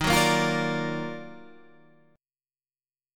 Listen to EbmM9 strummed